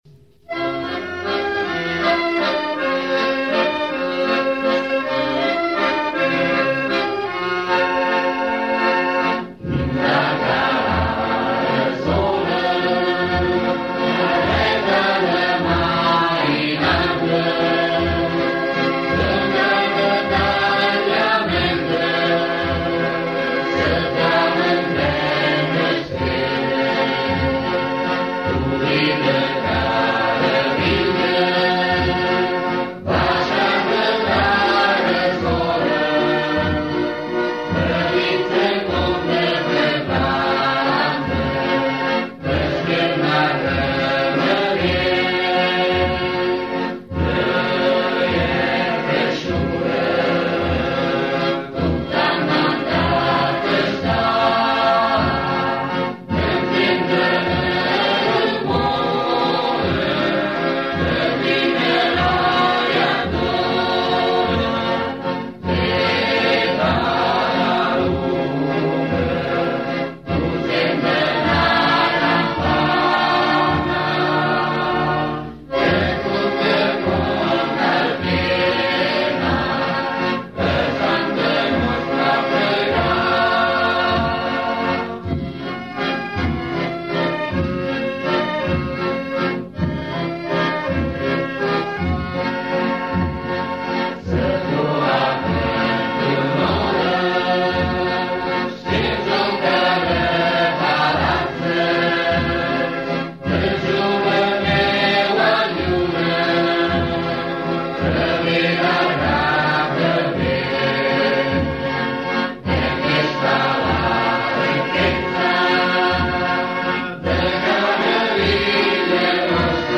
Musica (coro di Carovilli)